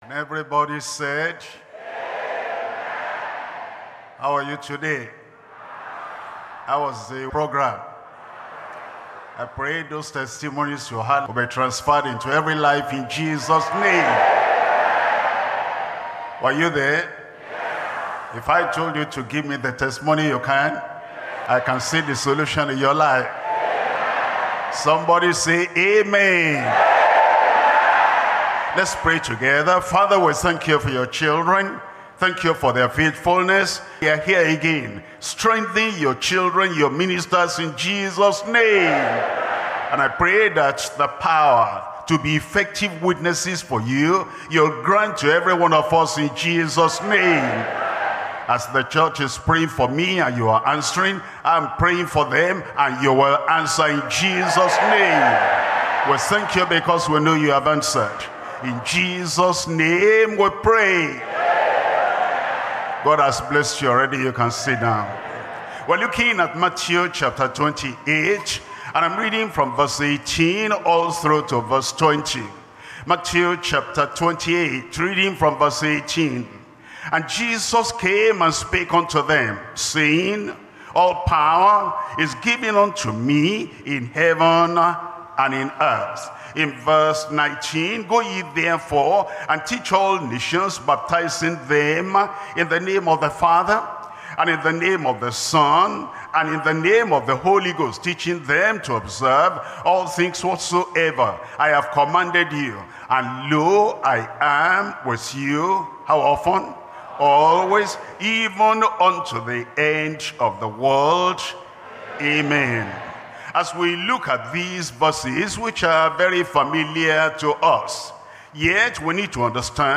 SERMONS – Deeper Christian Life Ministry Australia
2025 Workers Training